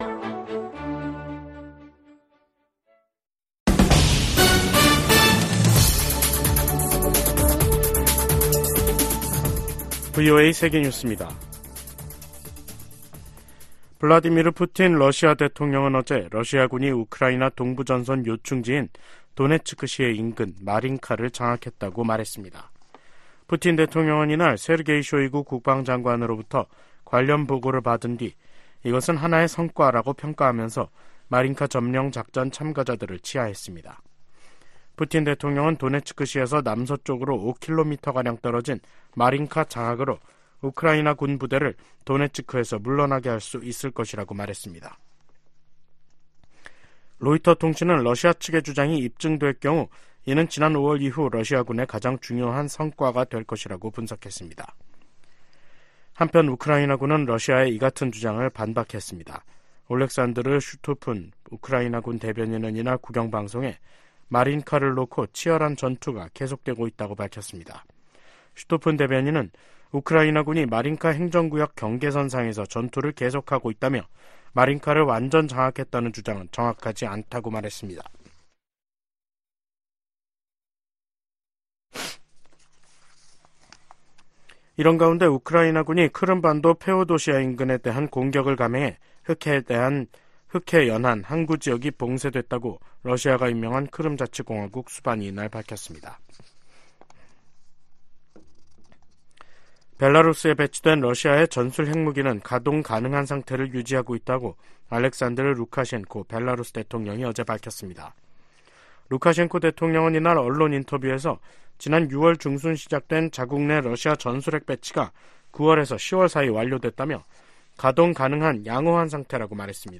VOA 한국어 간판 뉴스 프로그램 '뉴스 투데이', 2023년 12월 26일 2부 방송입니다. 조 바이든 미 대통령이 서명한 2024회계연도 국방수권법안에는 주한미군 규모를 현 수준으로 유지하는 내용과 한반도 관련 새 조항들이 담겼습니다. 북한이 영변의 실험용 경수로를 완공해 시운전에 들어간 정황이 공개되면서 한국 정부는 동향을 예의주시하고 있습니다. 유엔난민기구가 중국 정부에 탈북민의 열악한 인권 실태를 인정하고 개선할 것을 권고했습니다.